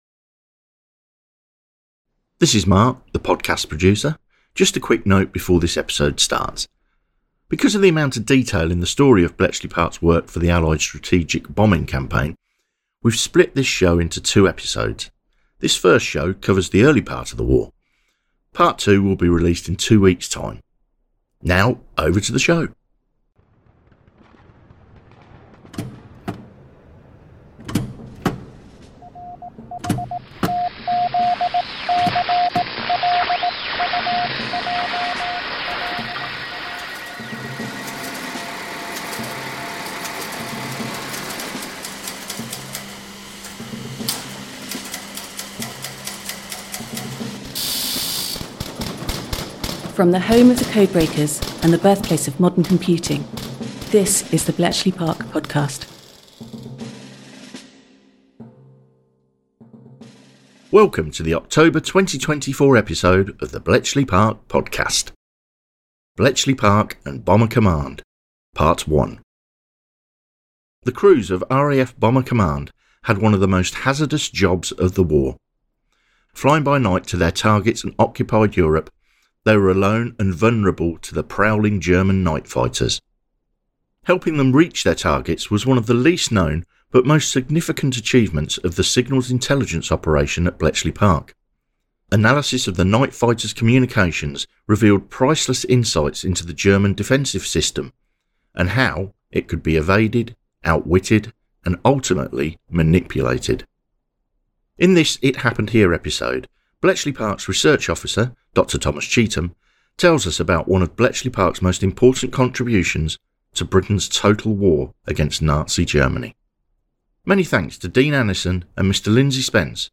This episode features the following Veteran from our Oral History archive: